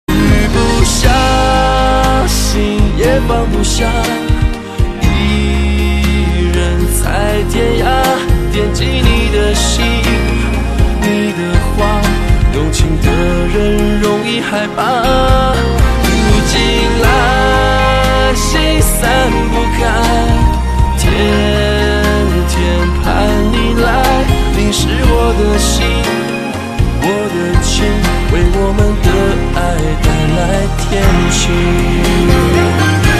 M4R铃声, MP3铃声, 华语歌曲 107 首发日期：2018-05-15 13:36 星期二